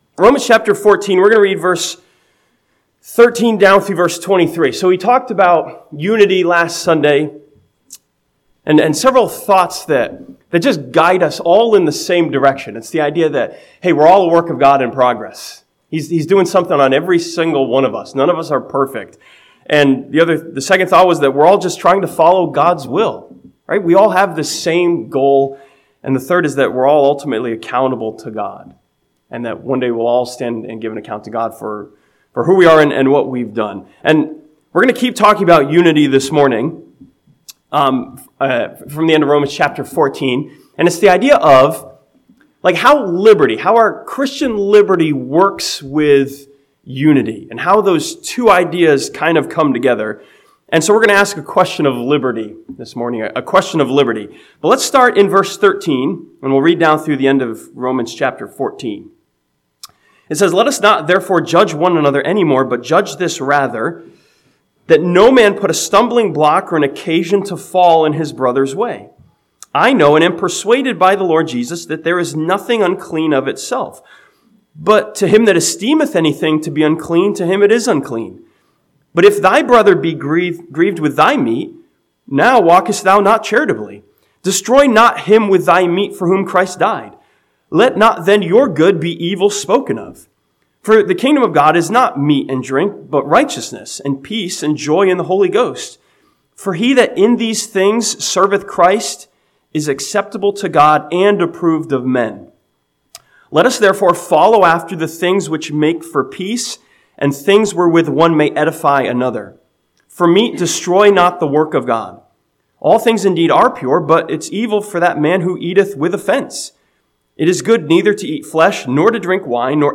This sermon from Romans chapter 14 challenges us with the question of whether our liberty is more important than unity or charity.